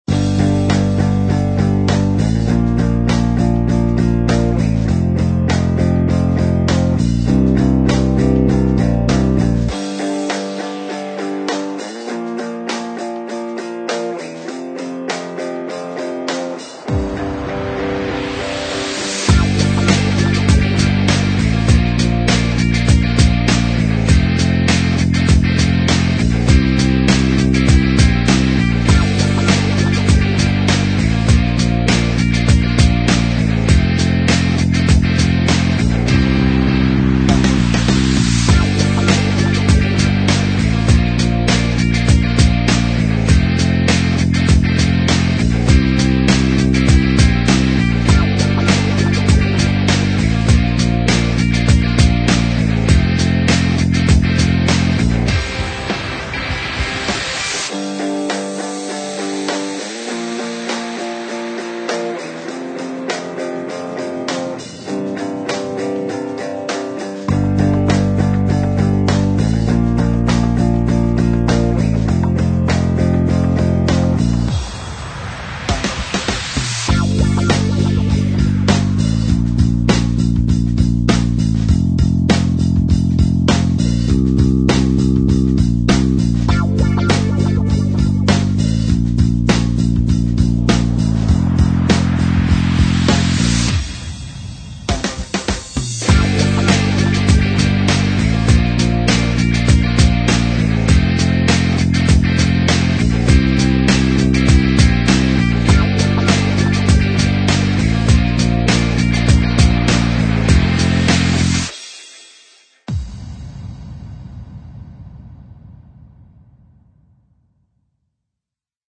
我急着想让大家注意我的新的，超级积极的快乐的企业动机轨道